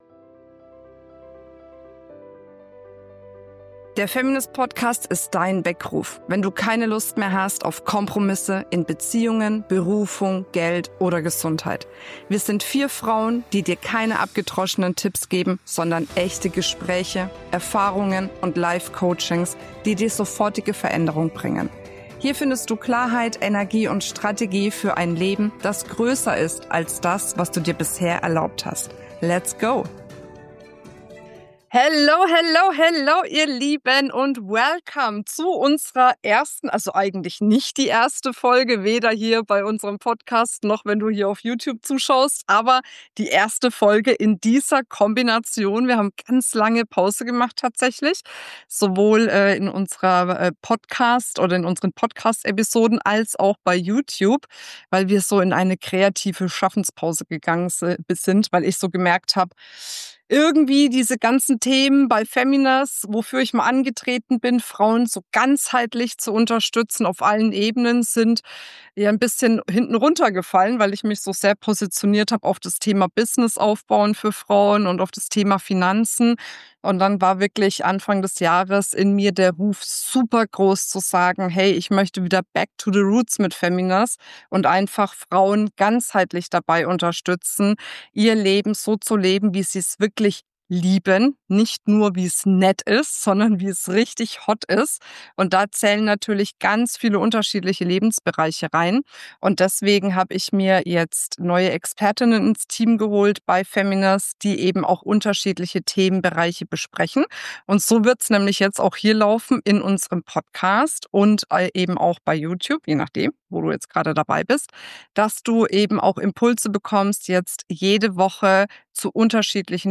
Wir sind vier Frauen, vier Perspektiven, vier Wahrheiten. Wir teilen keine abgedroschenen Tipps, sondern echte Erfahrungen, tiefe Gespräche und Live-Coaching-Momente mit Frauen aus der Community, die dich mitten ins Herz treffen.